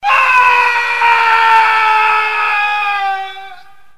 jingle3.mp3